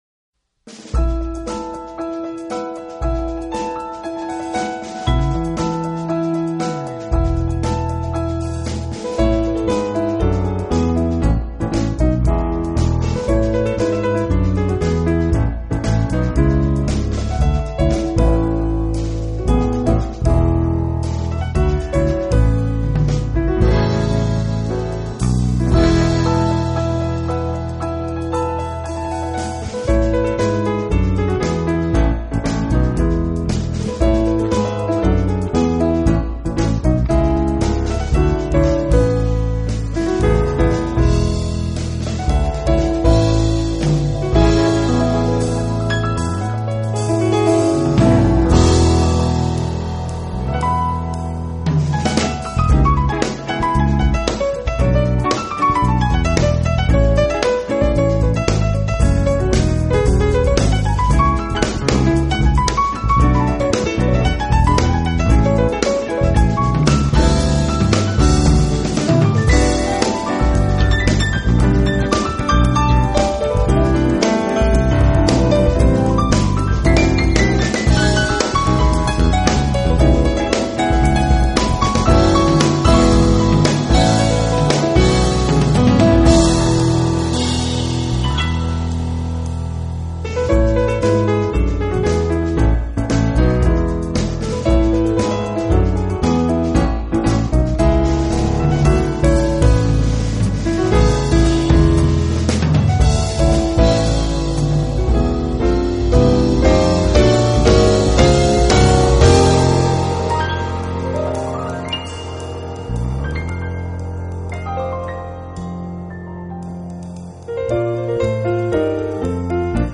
【现代爵士钢琴】
音乐风格：Jazz/Smooth Jazz/爵士 （CD 合辑）